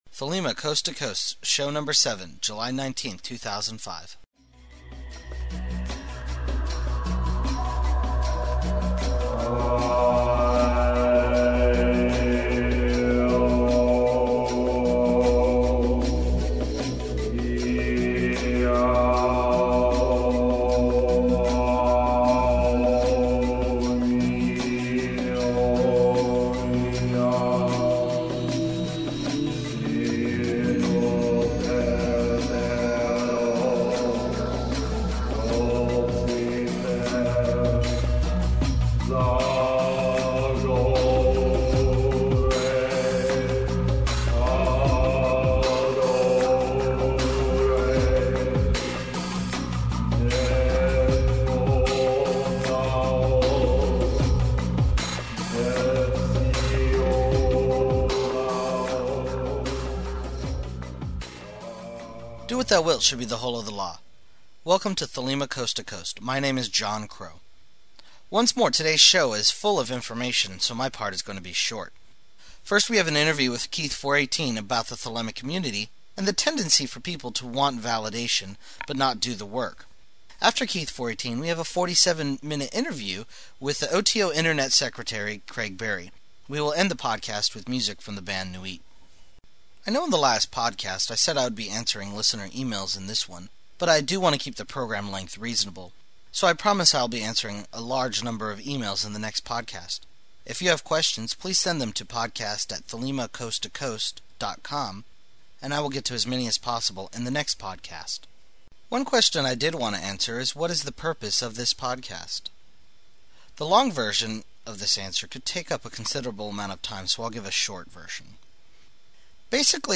Podcast tagline contest announcement. Closing remarks.